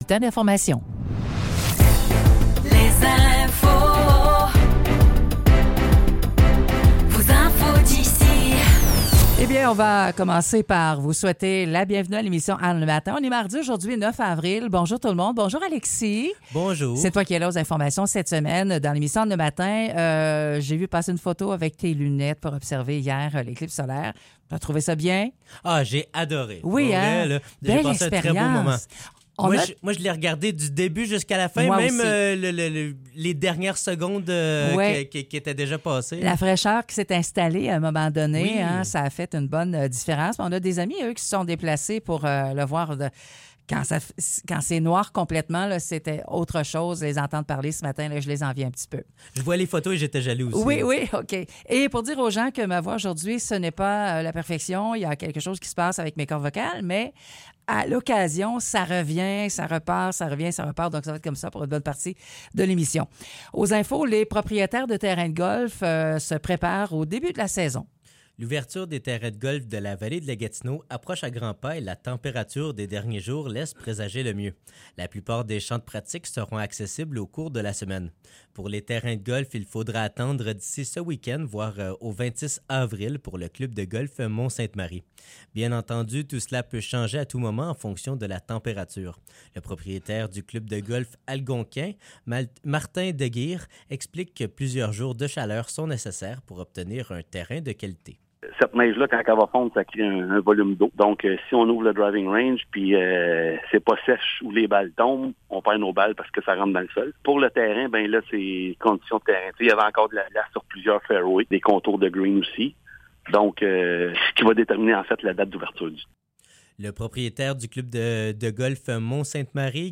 Nouvelles locales - 9 avril 2024 - 9 h